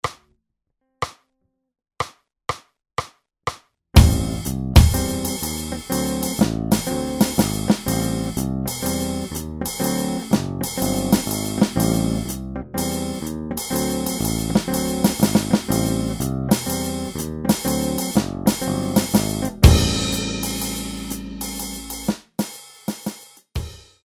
Vediamo diverse soluzioni ritmiche, mantenendo sempre il walking della mano Sx. uguale e variando, solo ritmicamente i voicing della mano Dx.